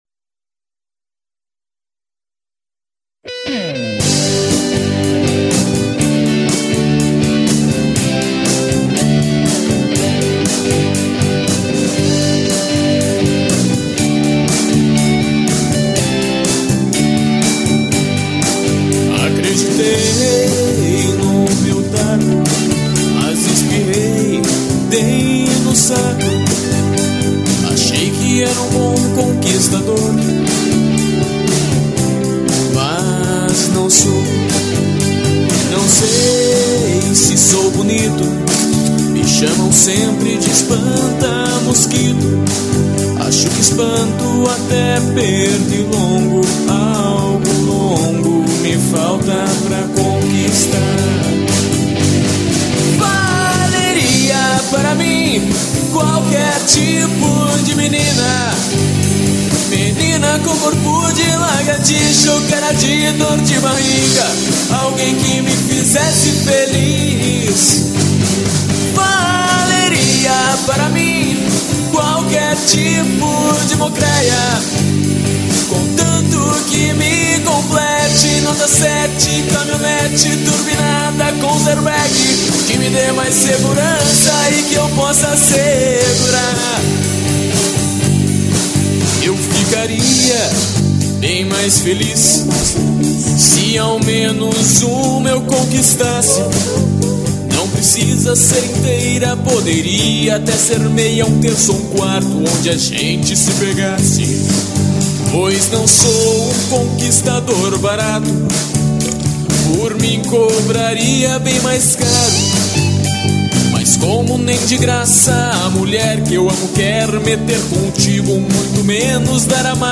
EstiloParódia / Comédia